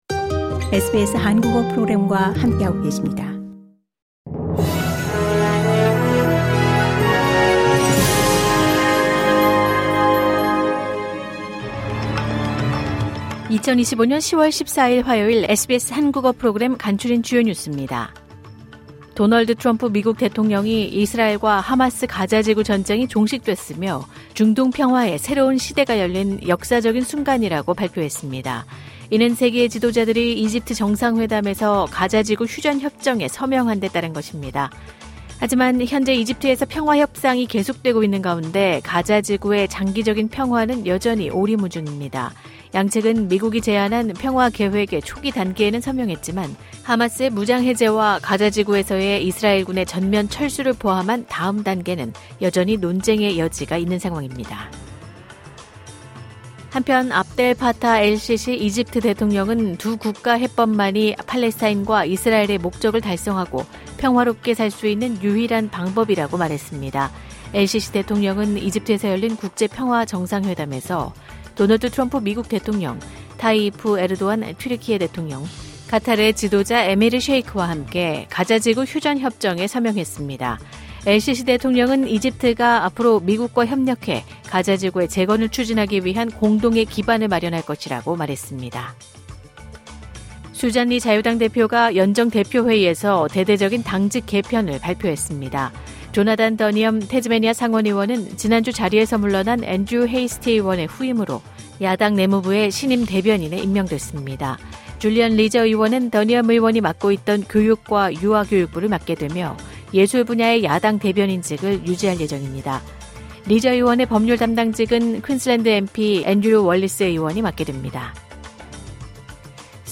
호주 뉴스 3분 브리핑: 2025년 10월 14일 화요일